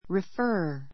rifə́ː r